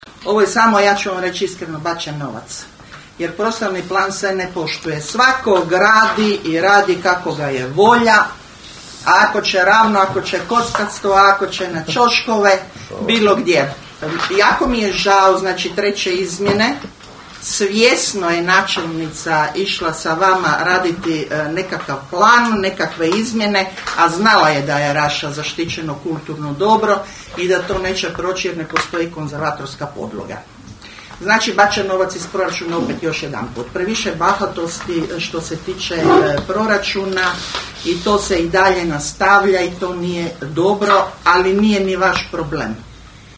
Sa sedam glasova za vijećnika vladajuće većine i šest glasova protiv oporbenih vijećnika na sinoćnjoj su sjednici Općinskog vijeća Raše prihvaćene IV. Izmjene i dopune Prostornog plana uređenja Općine Raša.